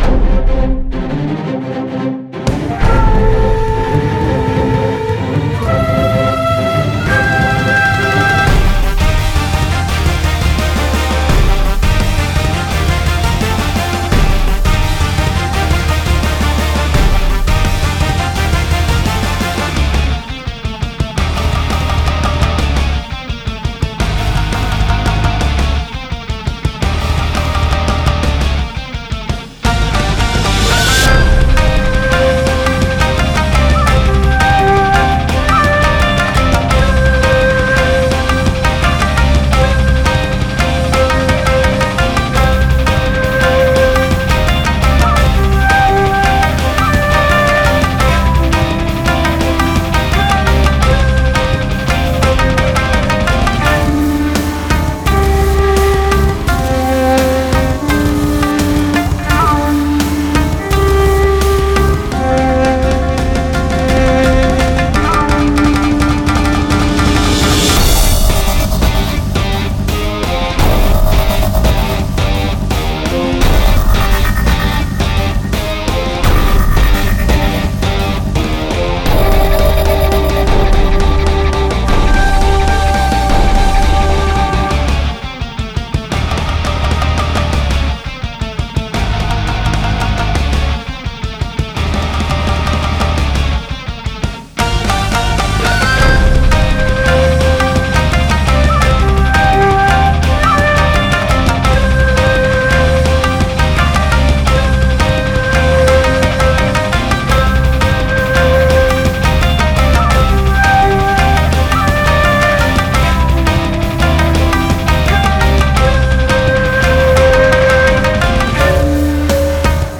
尺八と三味線が主旋律を担いシンセやエレキギターが重厚なバックを支える構成となっています。
• 使用楽器：尺八、三味線、エレキギター、和太鼓など
• 曲調：和風ロック × 電子音 × ダークで激しいテンポ（BPM 120〜130）